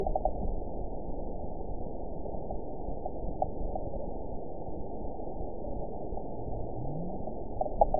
event 910749 date 01/31/22 time 01:00:04 GMT (3 years, 3 months ago) score 9.52 location TSS-AB05 detected by nrw target species NRW annotations +NRW Spectrogram: Frequency (kHz) vs. Time (s) audio not available .wav